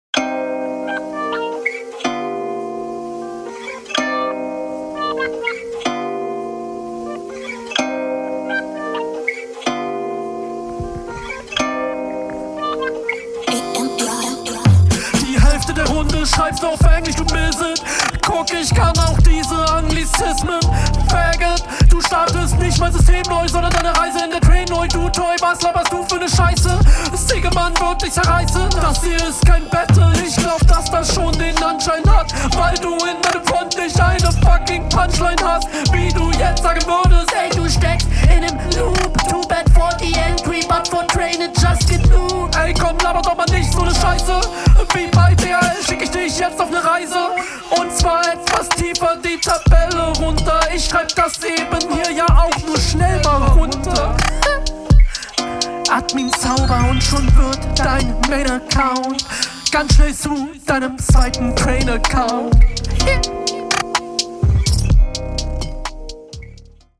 Flow:find ich gleich besser, viel mehr druck im flow, was für mich besser zu nem …
Flow: die Parts, die du durchziehst, sind echt cool, aber du baust immer mal weirde …